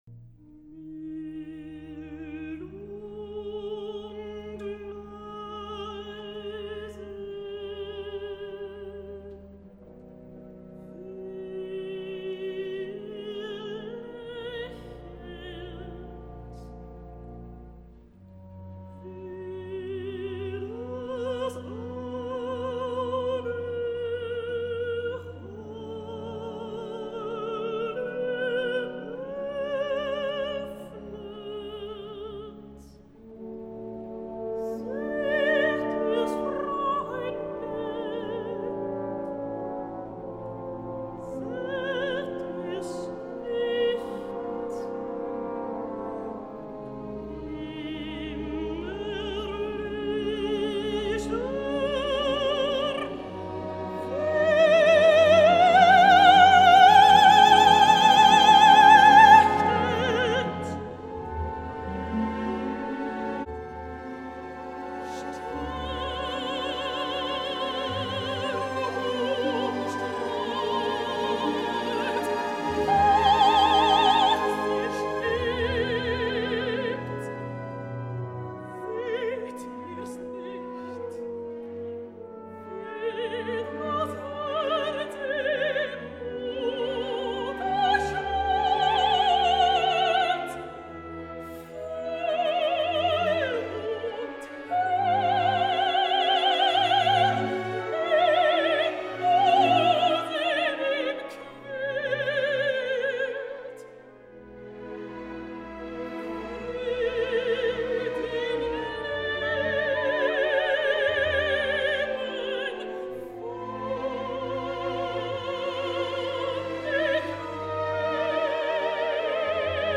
Escoltem ara 4 Isolde’s seguint l’esquema emprat amb els Wotan, són Waltraud Meier, Nina Stemme, Marta Mödl i Kirsten Flagstad cantant la mort d’Isolda, en gravacions dels anys 1994, 2005, 1952 i 1937 respectivament.